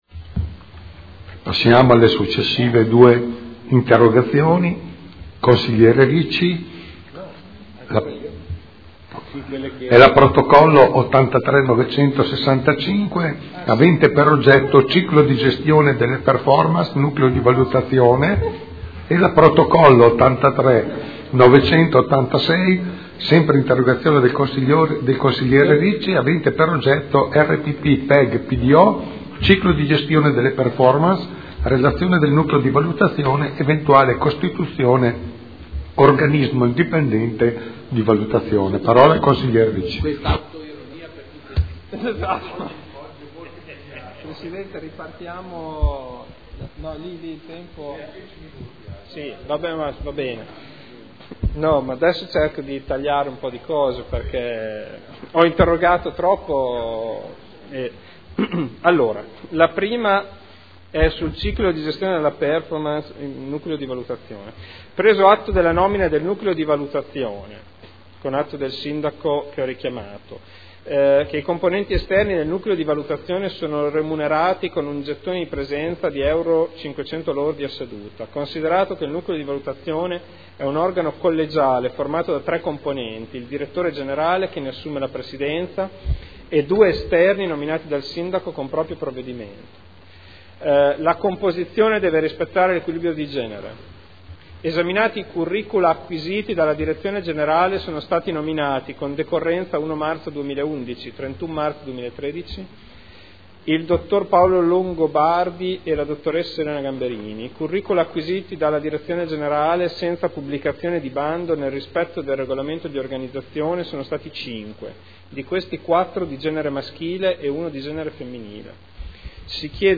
Seduta del 22/10/2012. Interrogazione del consigliere Ricci (Sinistra per Modena) avente per oggetto: "Ciclo di gestione della performance, Nucleo di Valutazione" e interrogazione del consigliere Ricci (Sinistra per Modena) avente per oggetto: "RPP - PEG - PDO, ciclo di gestione della performance - relazione del Nucleo di Valutazione, eventuale costituzione Organismo Indipendente di Valutazione"